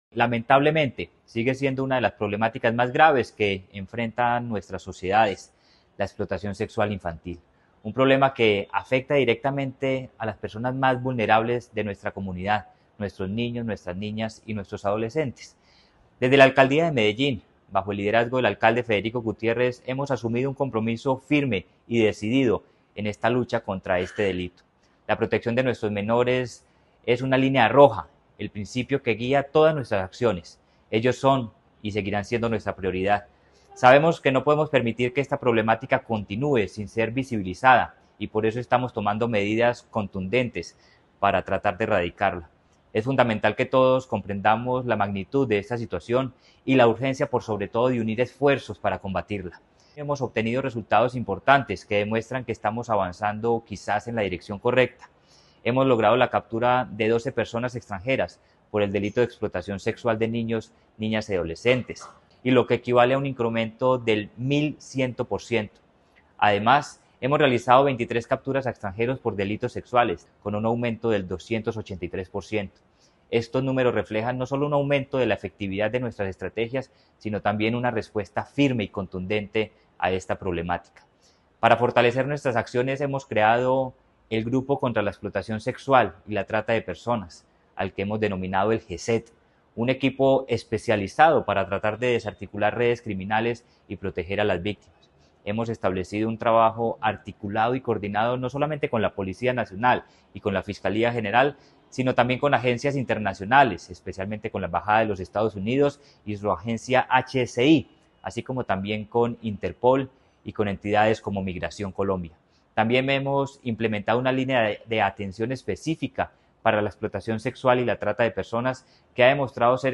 Palabras de Manuel Villa Mejía, secretario de Seguridad y Convivencia La Alcaldía de Medellín destaca los resultados alcanzados en la lucha contra la explotación sexual de niños, niñas y adolescentes.